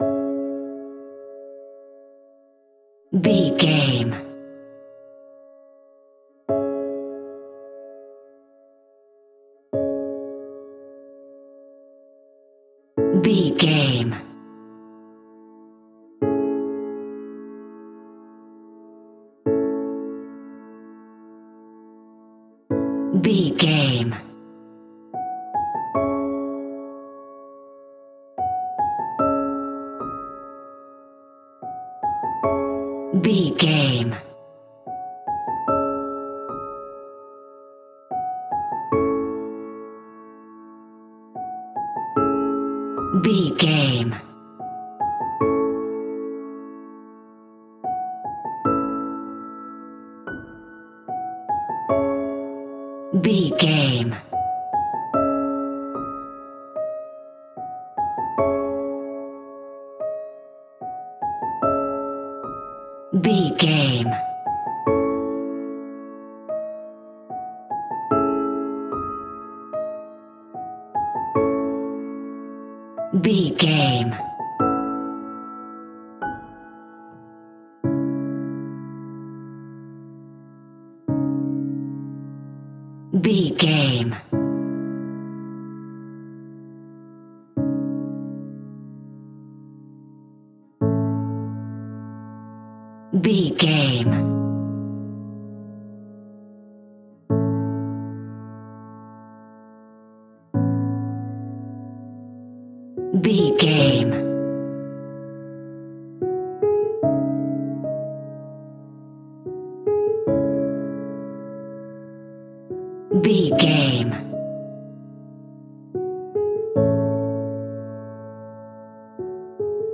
Aeolian/Minor
D♭
soft